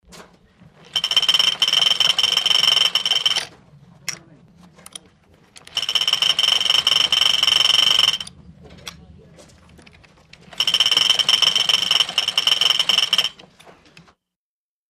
Military|Hand Cranks | Sneak On The Lot
Military field telephone crank handle